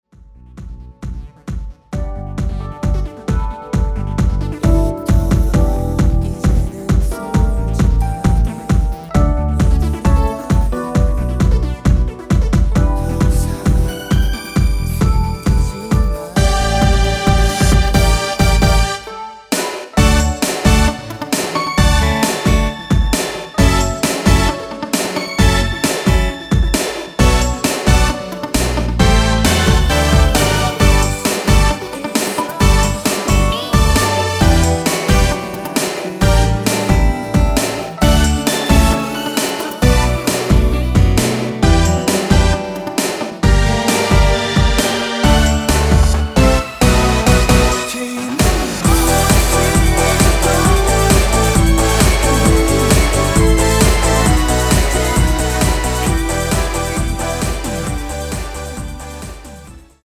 원키 코러스 포함된 MR입니다.
D
앞부분30초, 뒷부분30초씩 편집해서 올려 드리고 있습니다.
중간에 음이 끈어지고 다시 나오는 이유는